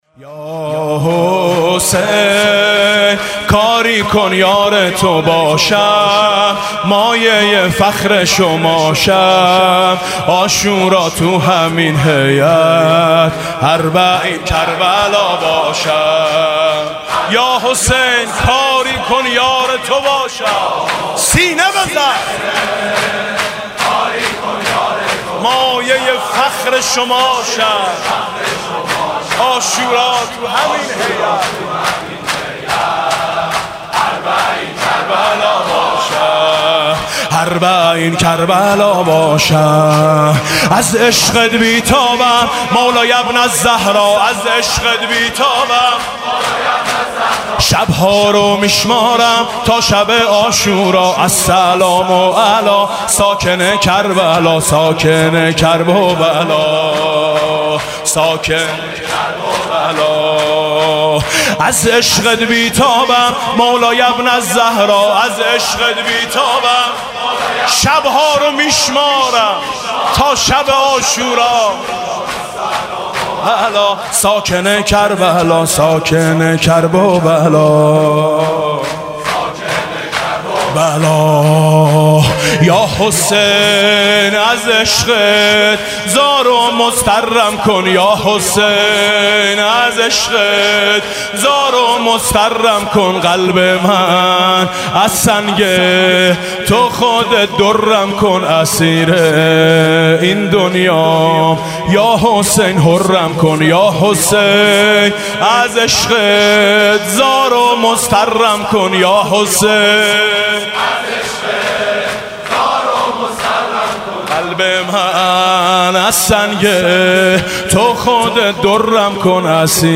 «محرم 1396» (شب پانزدهم) شور: یاحسین کاری کن یار تو باشم